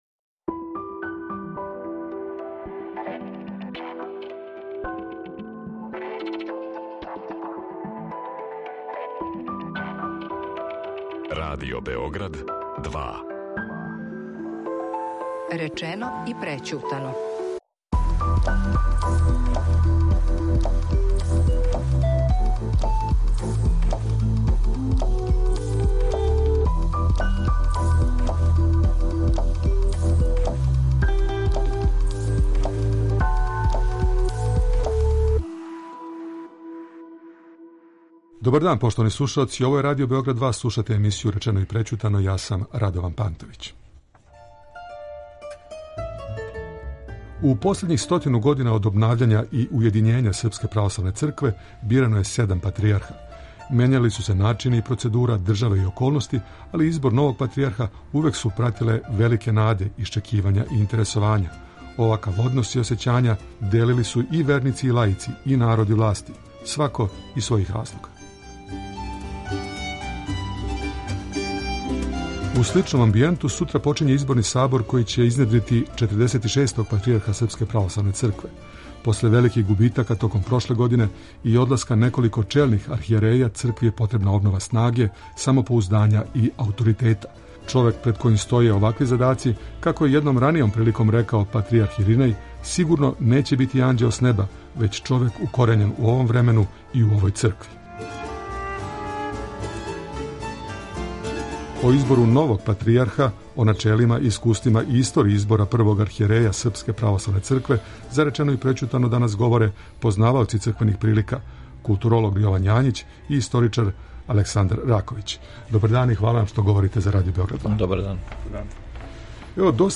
О избору новог патријарха, о начелима, искуствима и историји избора првог архијереја Српске православне цркве за Речено и прећутано говоре познаваоци црквених прилика